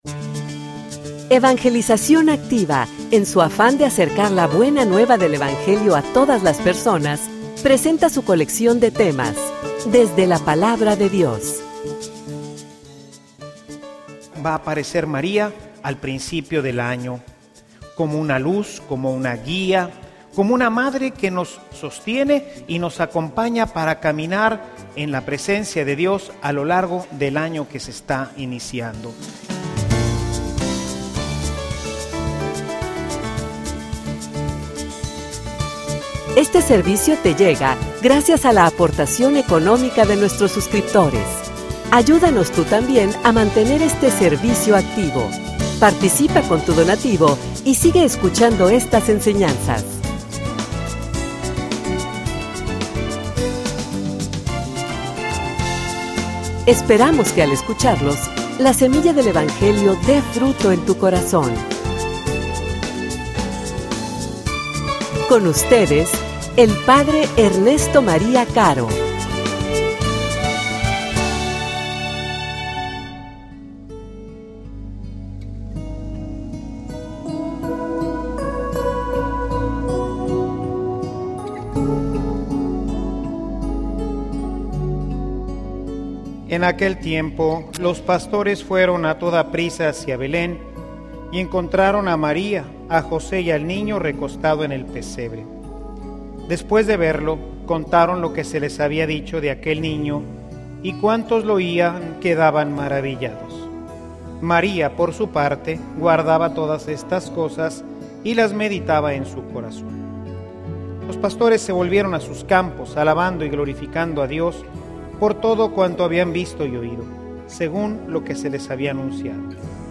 homilia_De_la_mano_de_Maria_Santisima.mp3